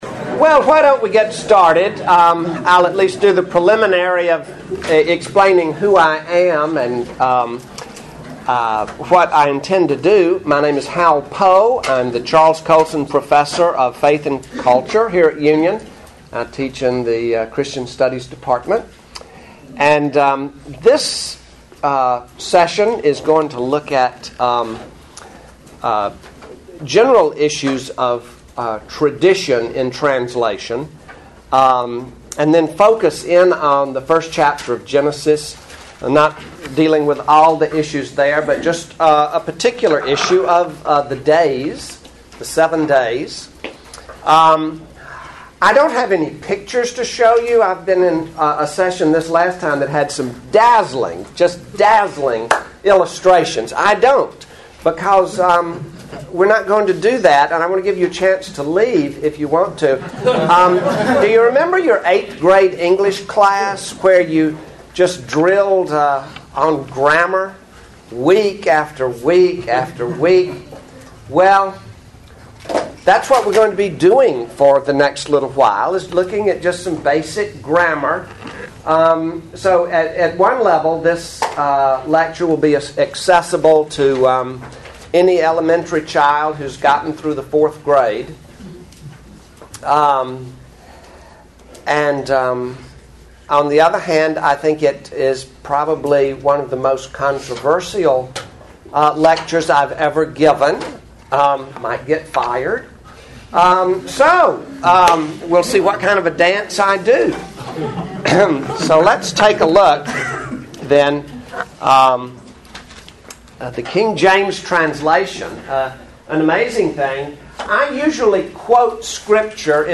KJV400 Festival
Address: The KJV and the Seven Days of Creation: When Tradition Conflicts with Text Recording Date